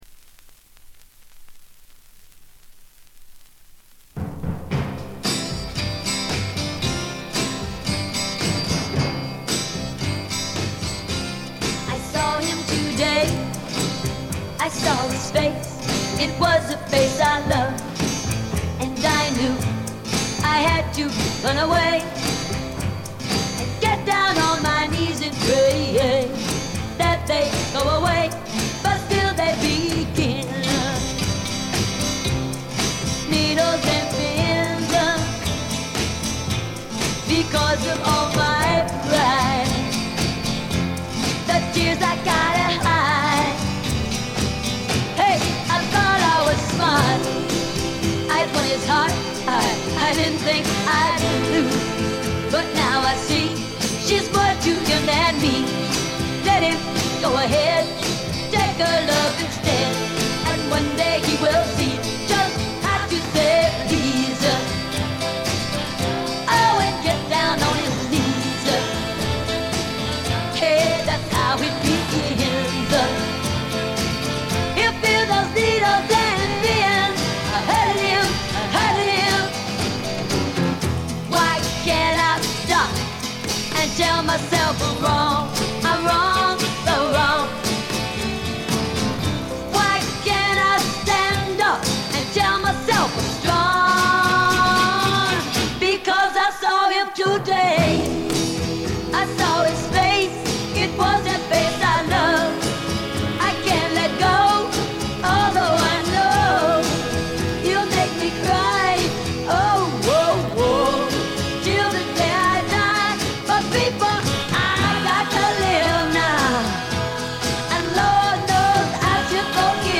B1フェードアウト〜無音部にかけて周回ノイズ（B2への影響は無し）。
存在感ありまくりのヴォーカルが素晴らしいです。
試聴曲は現品からの取り込み音源です。